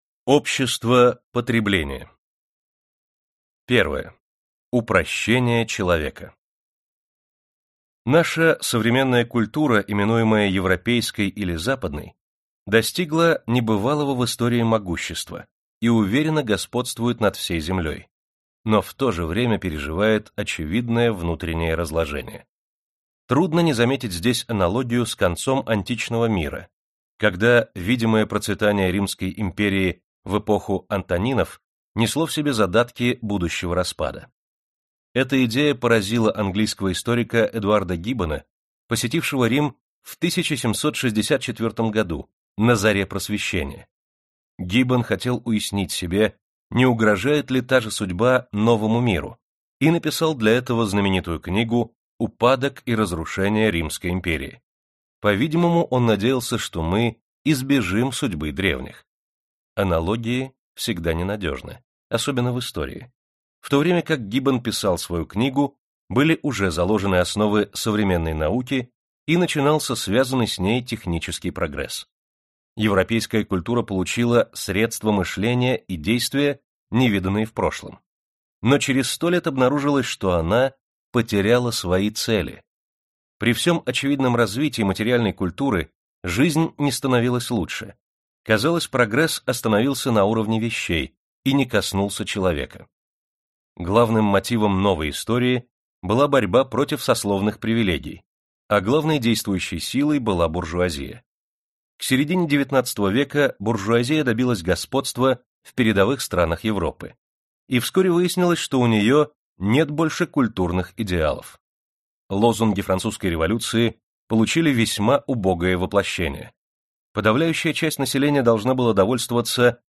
Аудиокнига Общество потребления | Библиотека аудиокниг